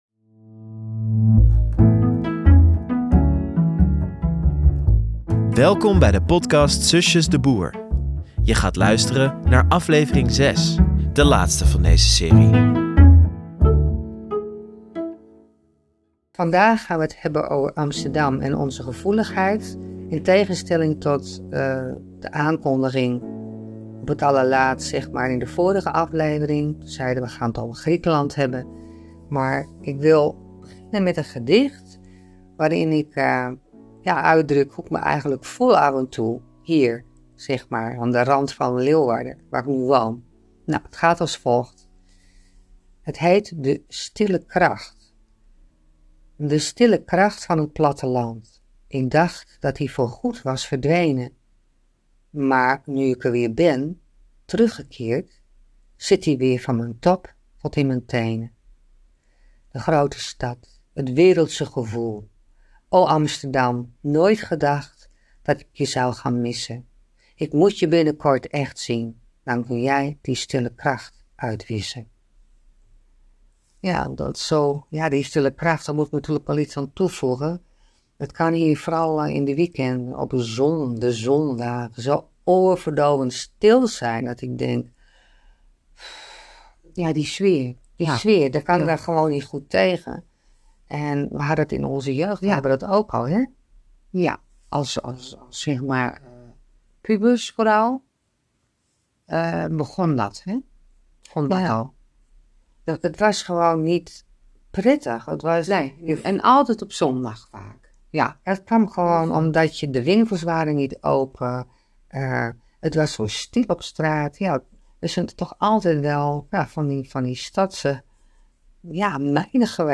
In deze aflevering wordt er ruimschoots aandacht geschonken aan het voordragen van diverse gedichten.